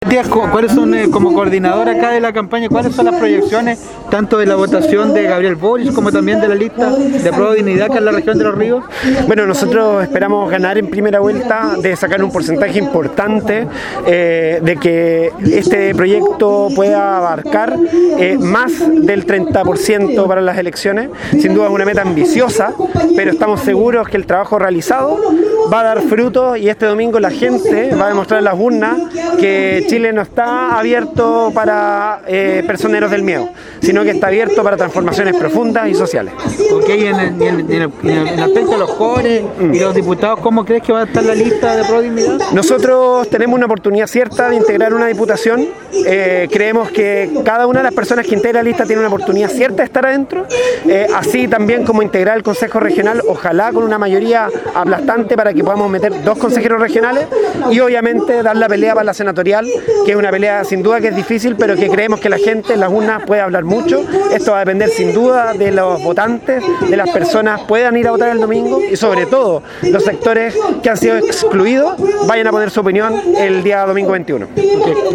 En un acto en la costanera de Valdivia Apruebo Dignidad cerró su campaña electoral
Unas 200 personas se dieron cita en el encuentro que tuvo música, malabares y llamados a la participación ciudadana en las elecciones de este domingo.